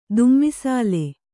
♪ dummisāle